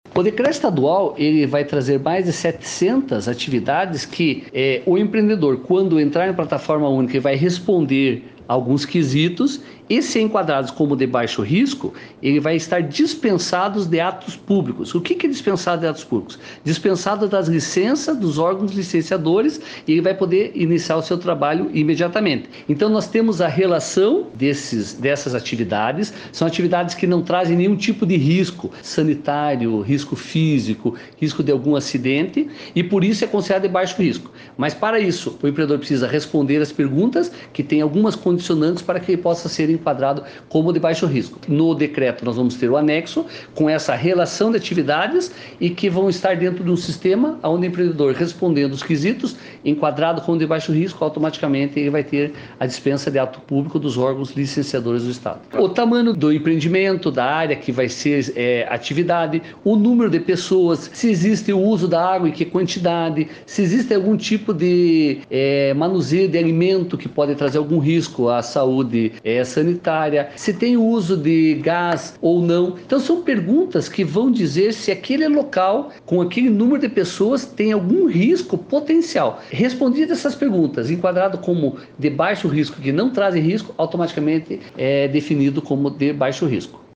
Sonora do presidente do Comitê de Desburocratização do Estado, coronel Jean Puchetti, sobre o decreto que dispensa licenças para mais de 770 atividades